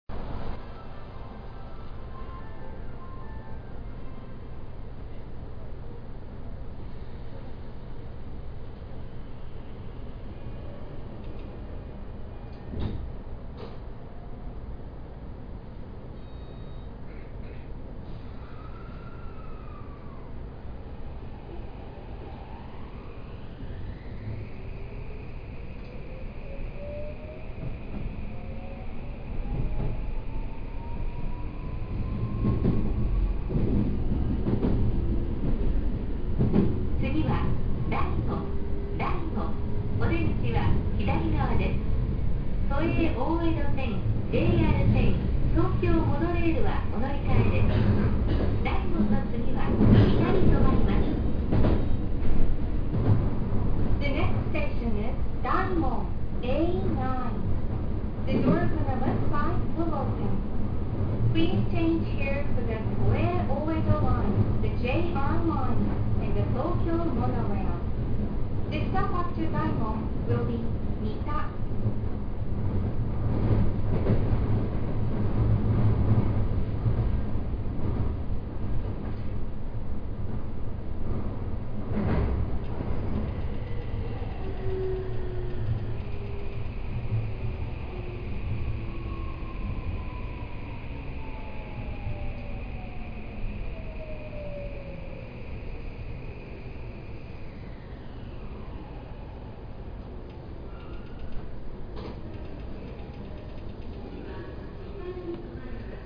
・5500形走行音
自動放送が搭載されているのは5300形と同様ですが、音質がとてもクリアなものとなった為聞こえやすくなりました。走行装置は三菱SiCのVVVF。なかなか特徴的な音で、近年の新型車としてはかなり個性が出ている部類なのではないでしょうか。
shinbashi-daimon.mp3